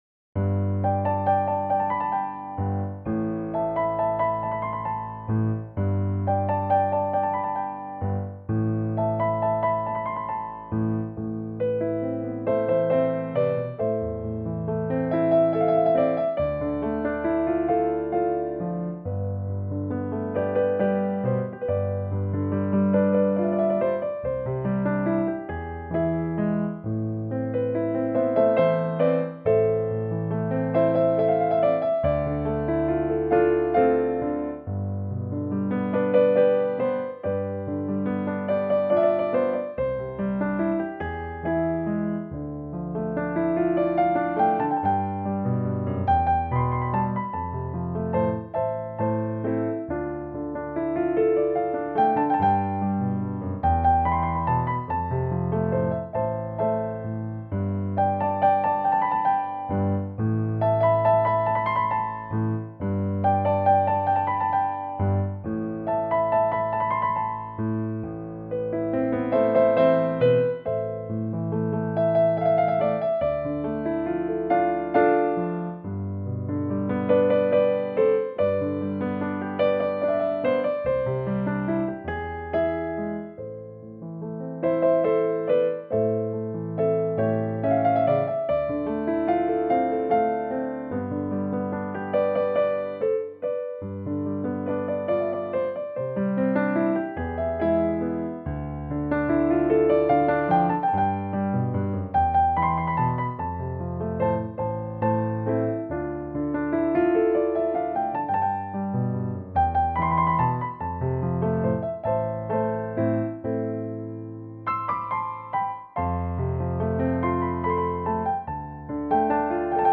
Solo Piano